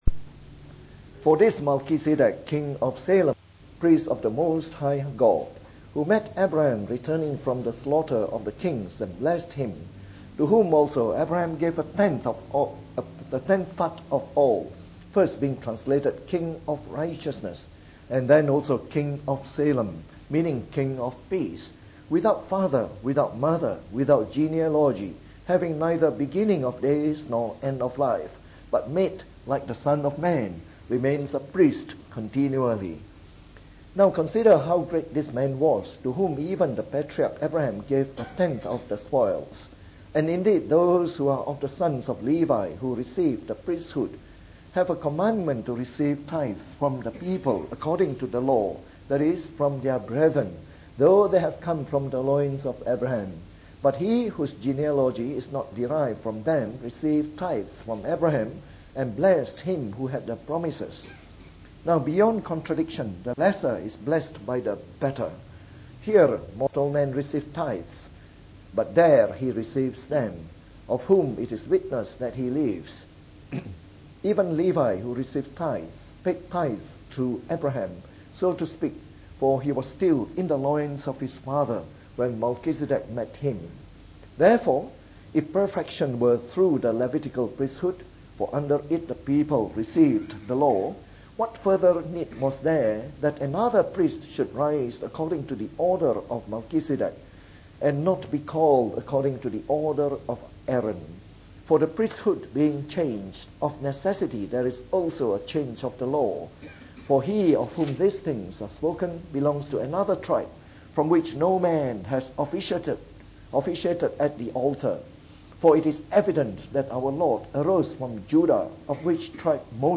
From our series on the “Epistle to the Hebrews” delivered in the Evening Service.